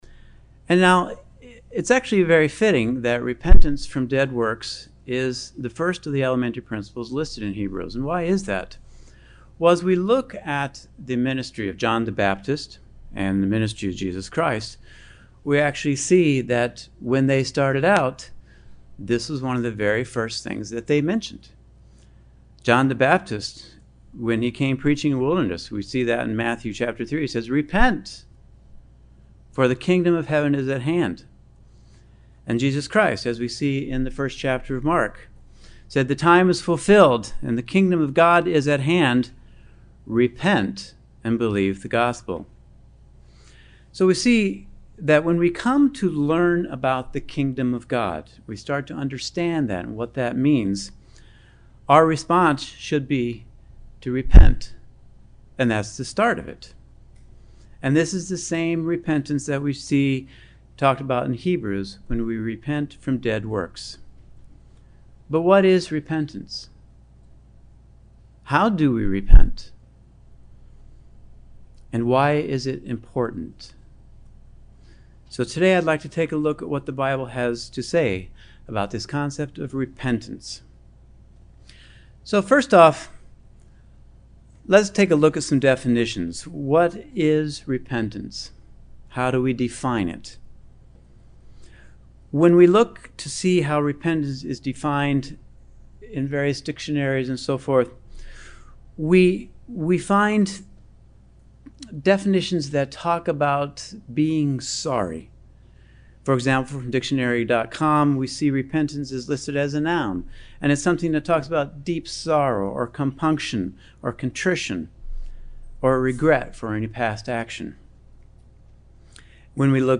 What is repentance and why do we need to repent? This sermon examines the topic of repentance from dead works